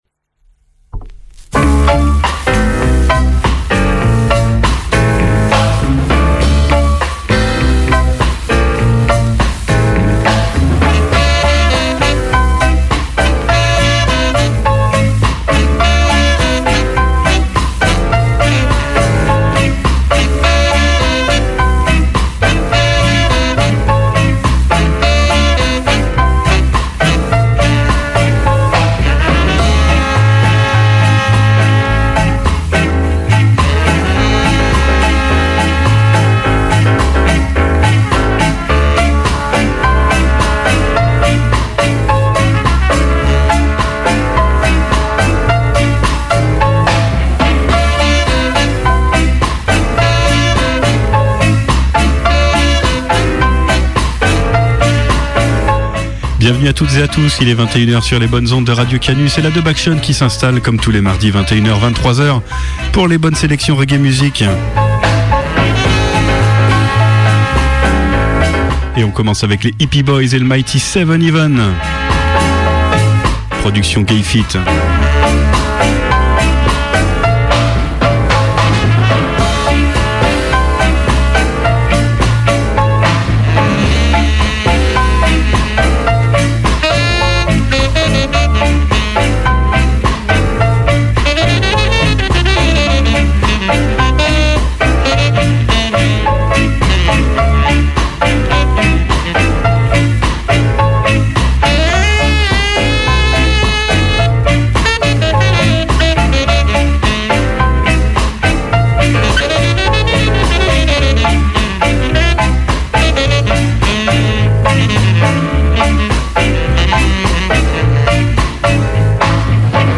vinyl selection